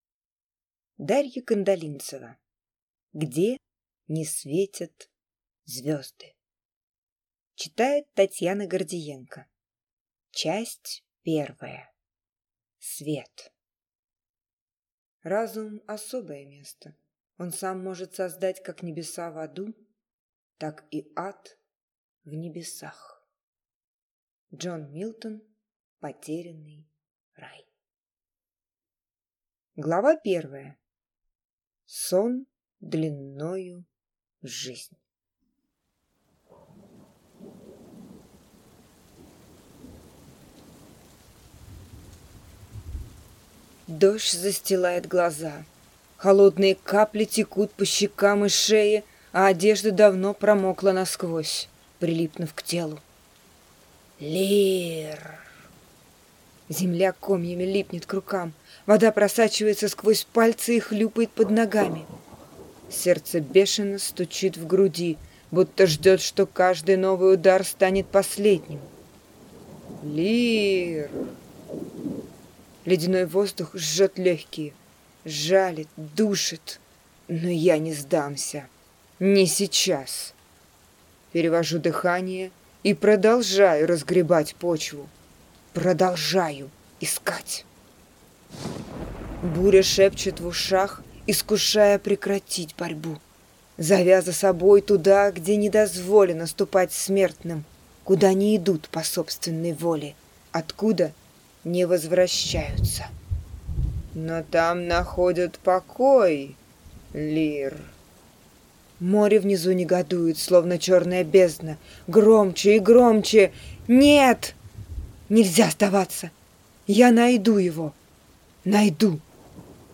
Аудиокнига Где не светят звезды | Библиотека аудиокниг